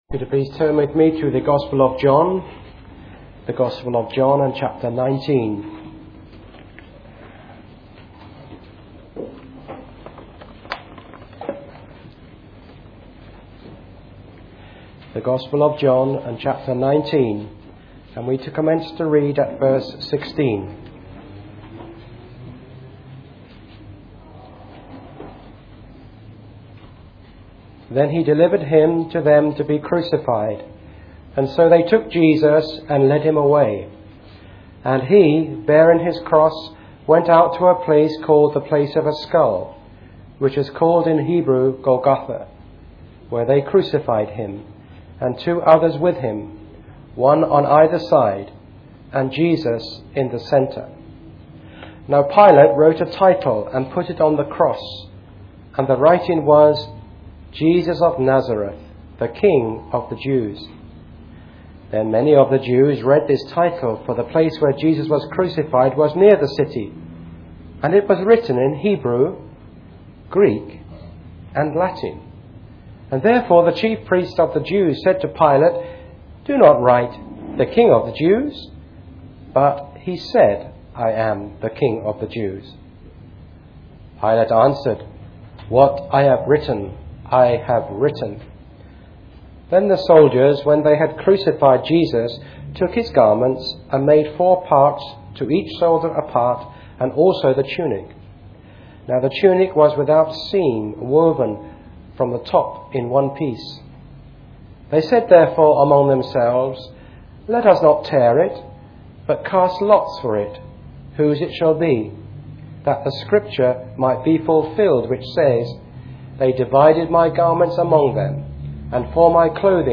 Preached on the 2nd of September 2012.